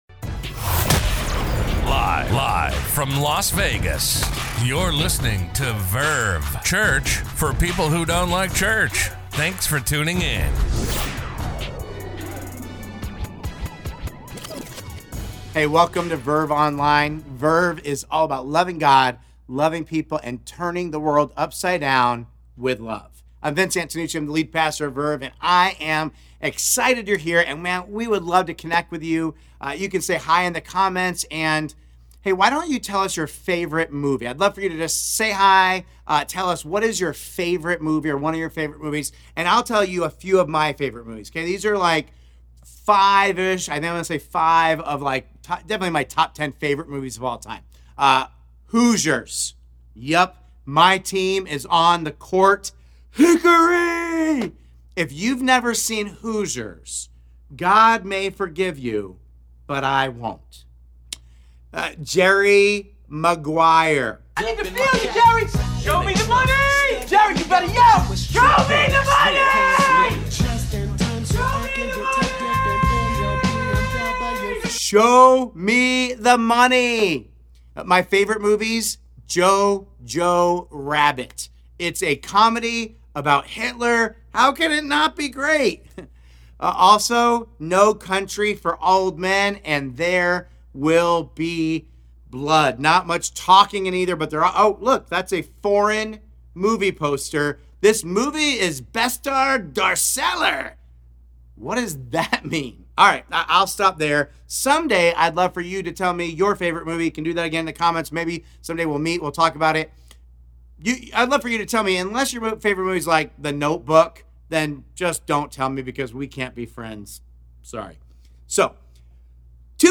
A message from the series "In The Shadows."